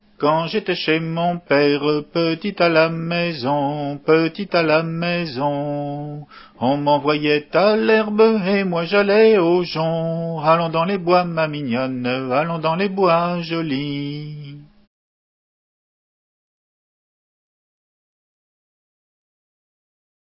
Hanter dro
Chanteurs des Pays de Vilaine
Entendu au fest noz de Redon en octobre 89